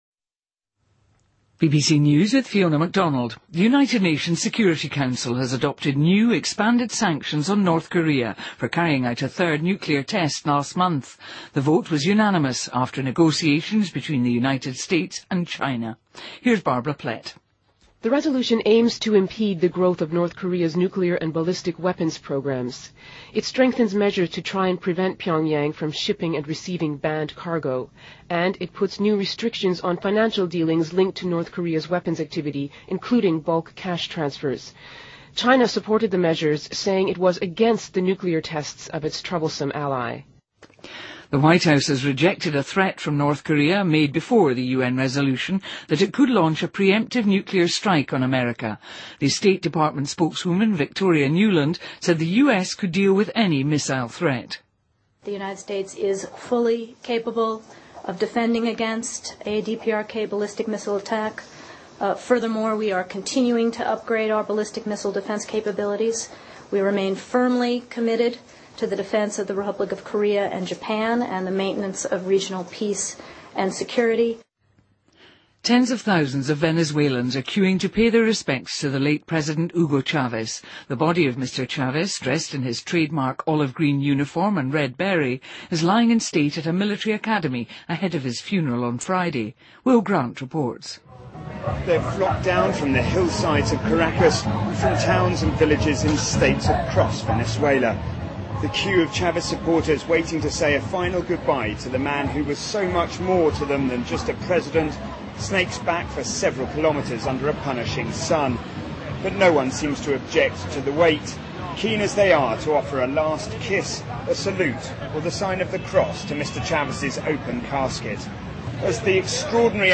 BBC news,2013-03-08